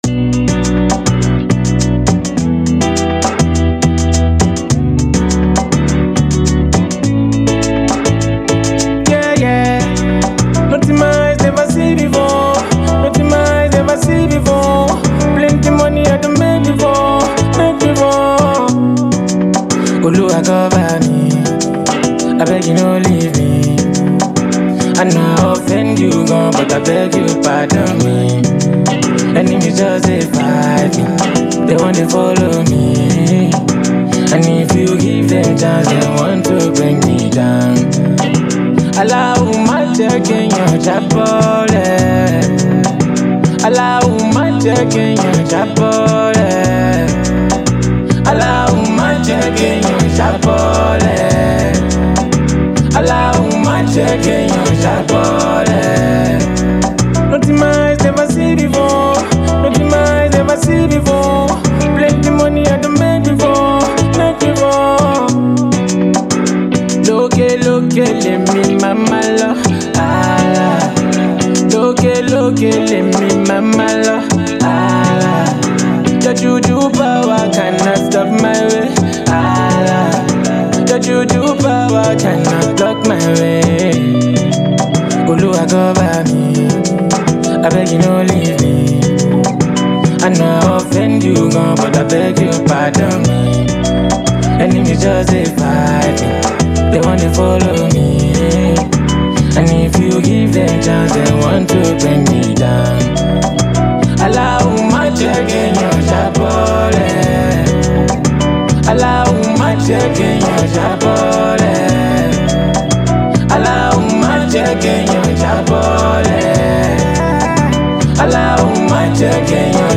Extremely talented Nigerian singer